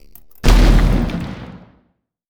Grenade2.wav